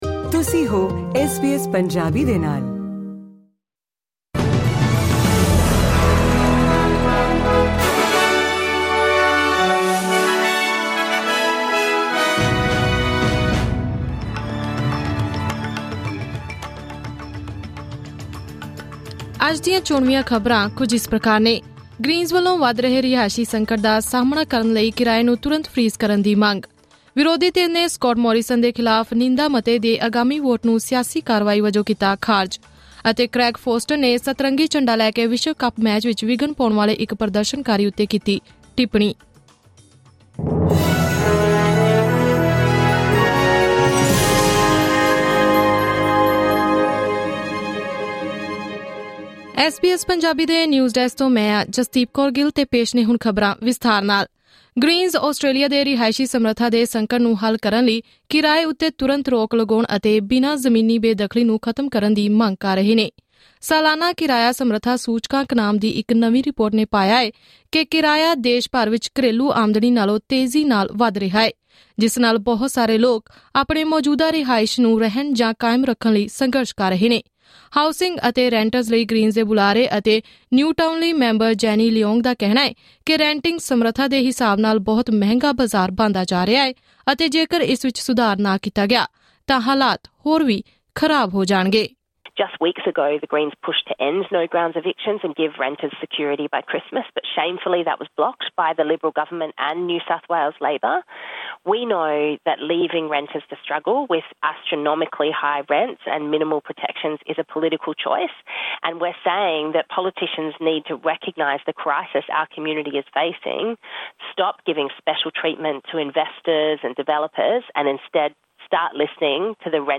Presenting the major national and international news stories of today; sports, currency exchange details and the weather forecast for tomorrow. Click on the audio button to listen to the full bulletin in Punjabi.